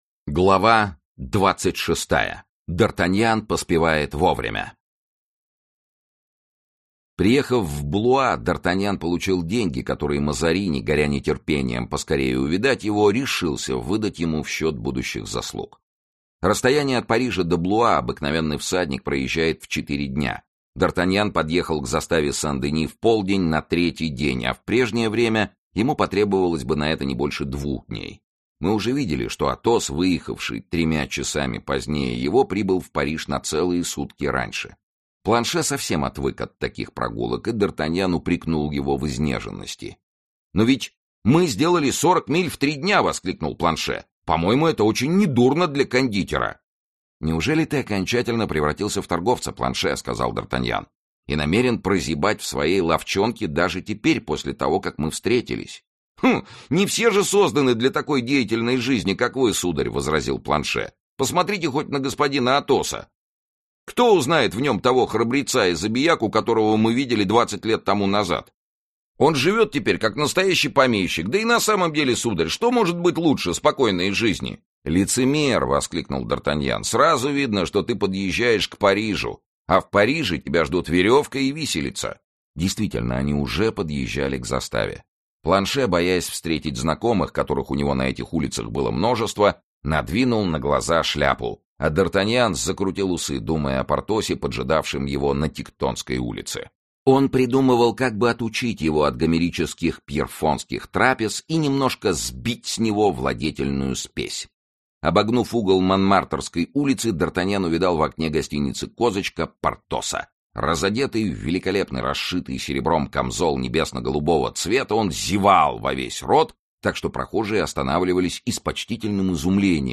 Аудиокнига Двадцать лет спустя. Часть 2 | Библиотека аудиокниг
Aудиокнига Двадцать лет спустя. Часть 2 Автор Александр Дюма Читает аудиокнигу Сергей Чонишвили.